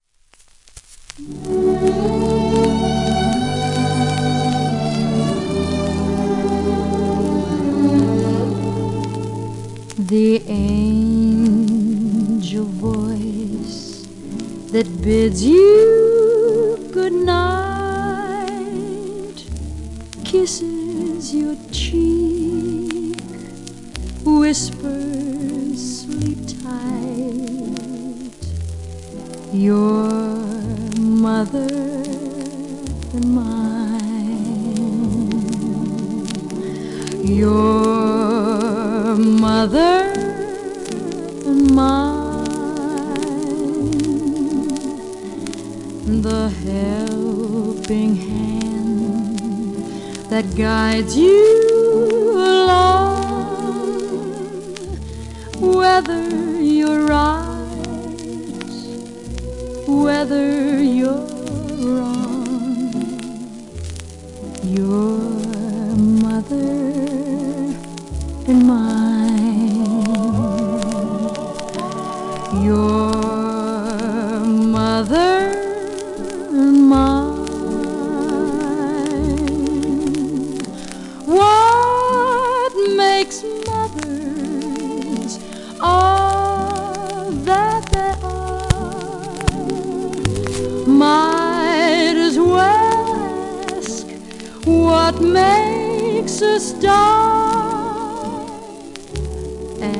盤質B+ *エッジチップ、キズ、フォルテ荒、面スレ
チップは音溝ギリギリ,キズは数回クリック音有
1952年録音